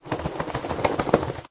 minecraft / sounds / mob / wolf / shake.ogg
shake.ogg